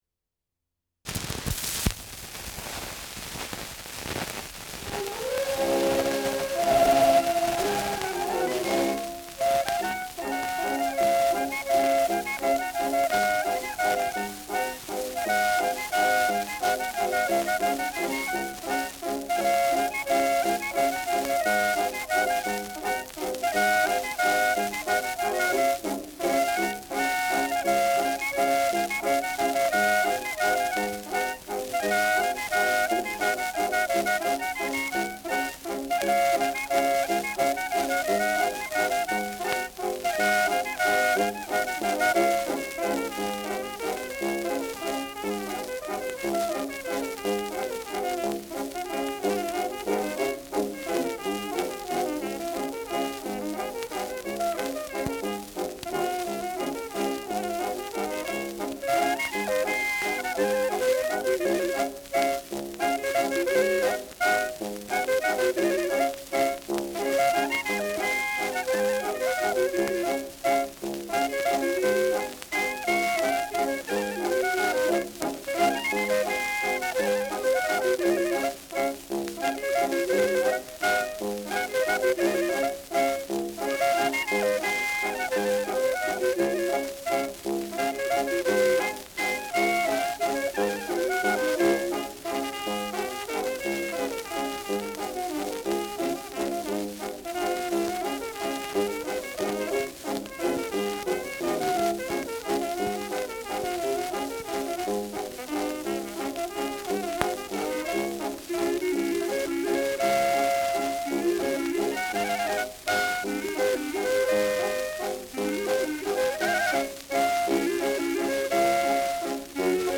Schellackplatte
Anfang stark verrauscht : Stärkeres Grundrauschen : Gelegentlich leichtes bis stärkeres Knacken
Dachauer Bauernkapelle (Interpretation)
[München] (Aufnahmeort)